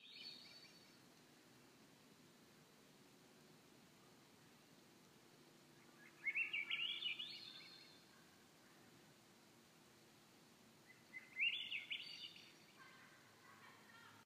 Swainson’s Thrush
I heard this haunting melody and was captivated.
The call is heard in the early morning and late evening, just when the sun in making it’s entrance and exit to the day.
It has an upward, spiraling melody that is haunting and beautiful.
swainsons-thrush.m4a